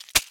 Звук удара жалом скорпиона